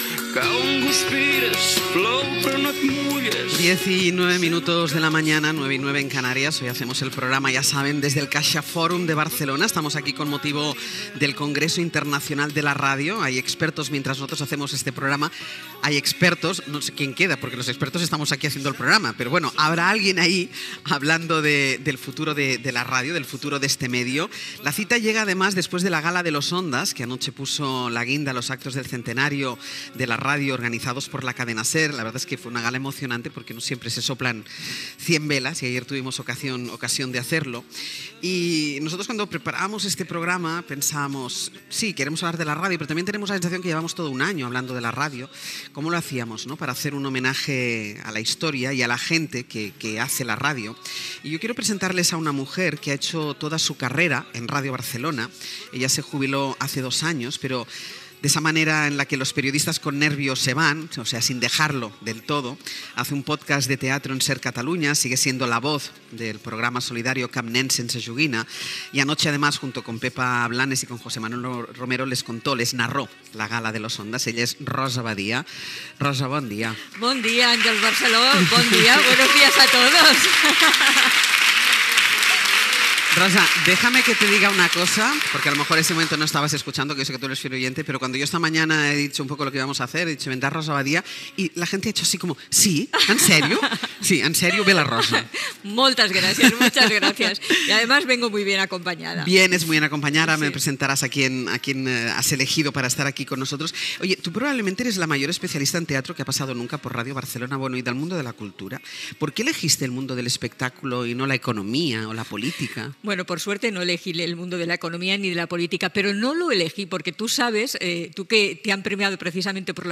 Programa fet des de Caixa Fòrum Barcelona amb motiu del Congrés Internacional de la Ràdio.
Info-entreteniment